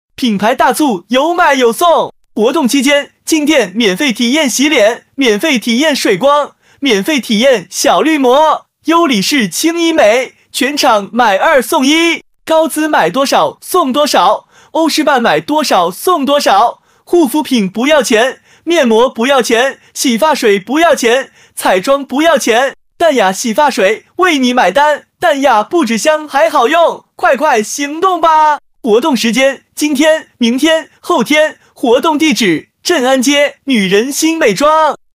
优质特价男-激情有力 激情力度